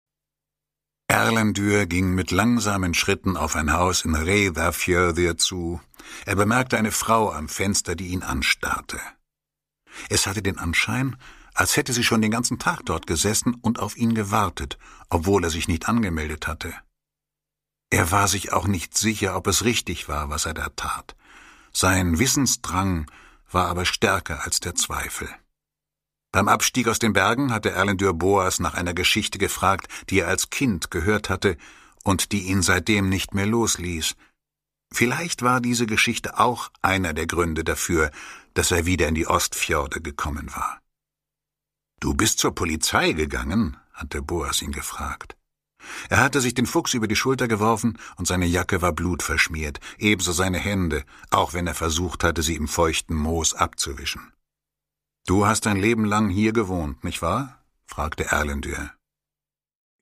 Produkttyp: Hörbuch-Download
Gelesen von: Walter Kreye